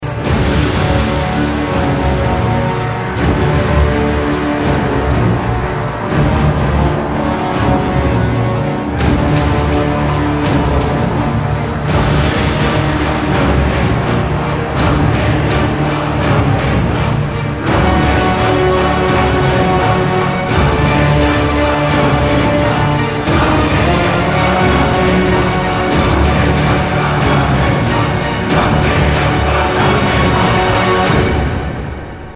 Marcha de los muertos